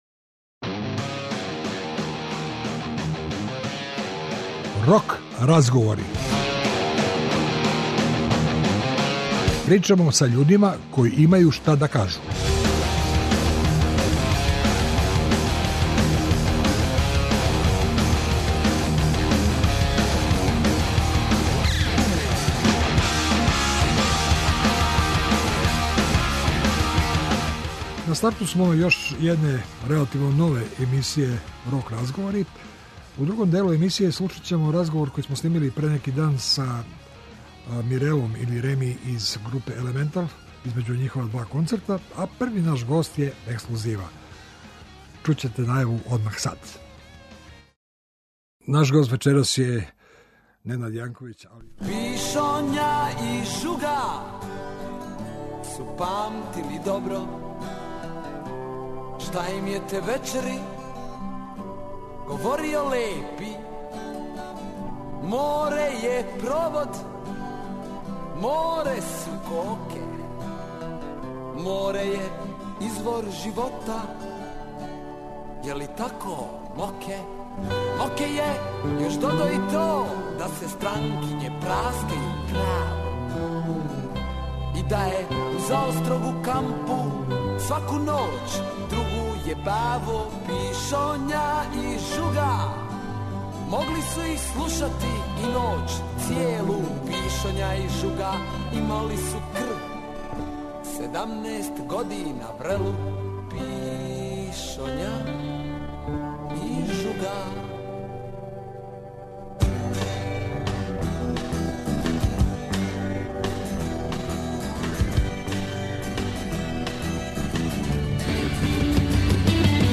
Овог четвртка нам у госте долази ДР НЕЛЕ КАРАЈЛИЋ - поводом нове књиге СОЛУНСКА 28 али и концерата који следе.